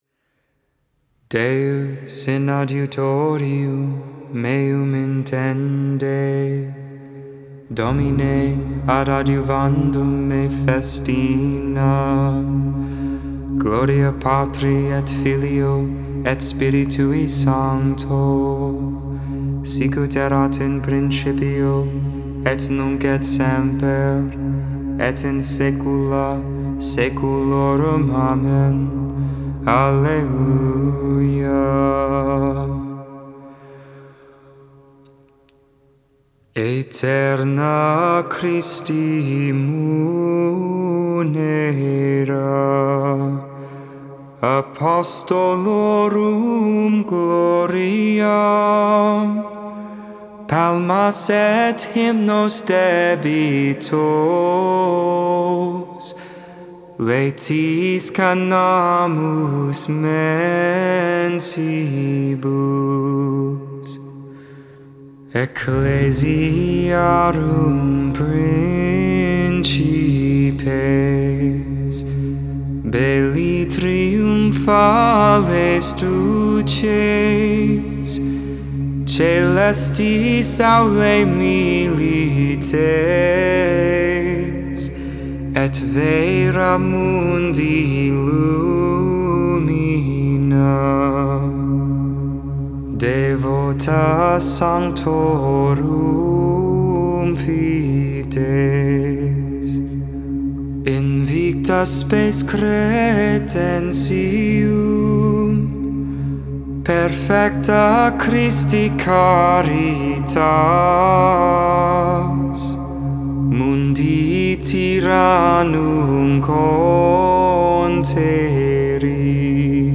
Monday Vespers, Evening Prayer for the 5th Monday in Eastertide on the Feast of Philip and James.
Magnificat: Luke 1v46-55 (English, tone 8) Intercessions: Be mindful of your Church, O Lord.